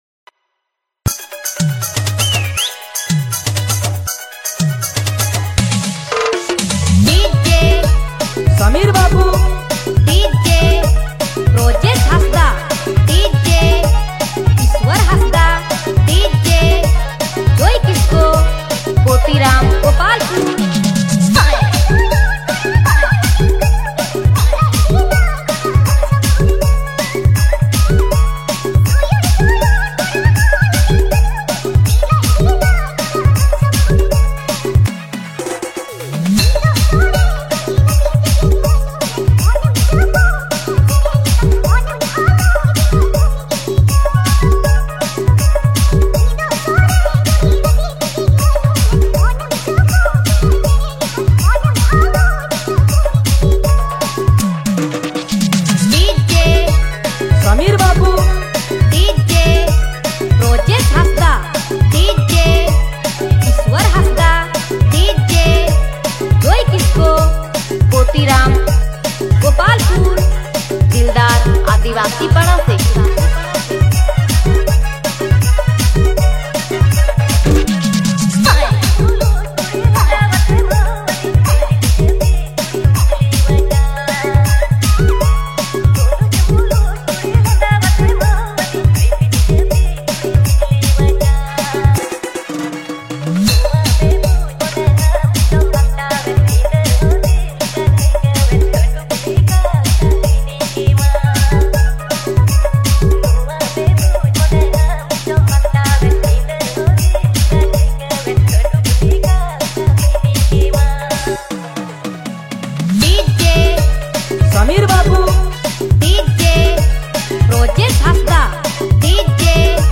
Dj Remixer
New Santali Dj Songs